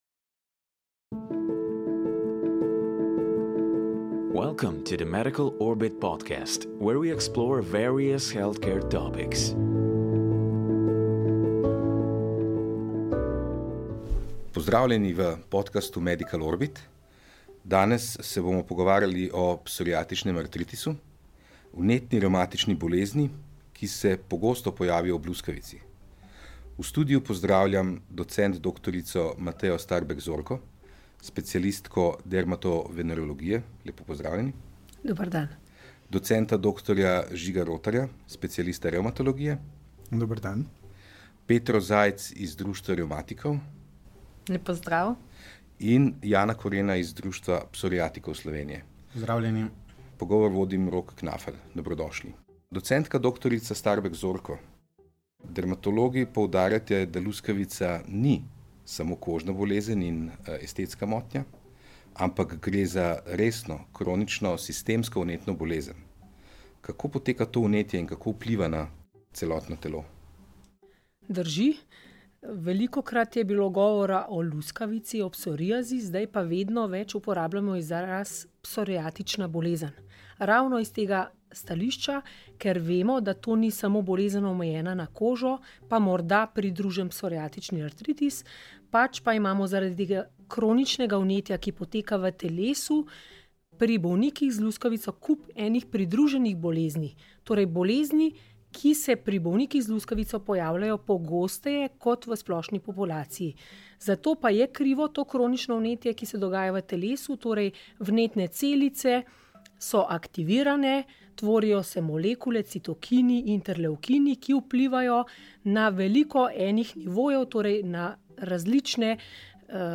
V podkastu Medical Orbit so se sogovorniki pogovarjali o psoriatičnem artritisu, vnetni revmatični bolezni, ki se pogosto pojavi ob luskavici.